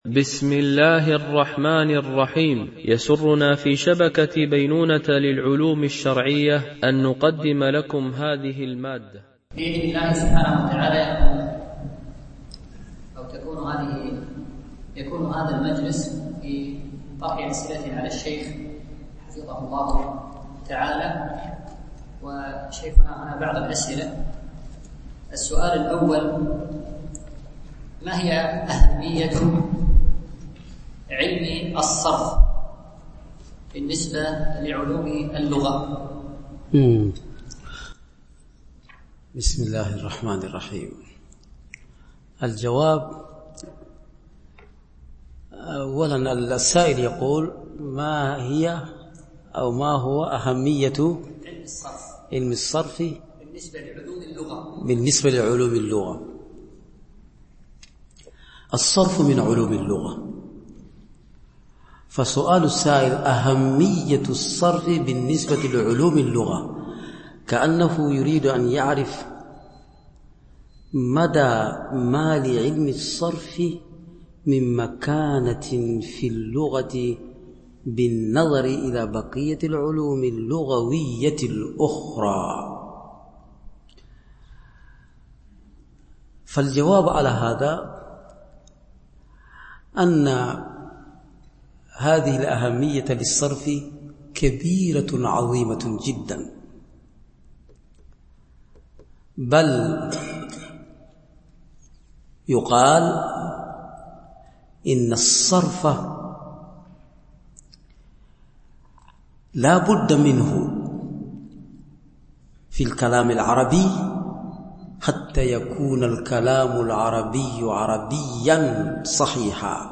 دورة علمية في شرح متن البناء في الأفعال
بمسجد عائشة أم المؤمنين - دبي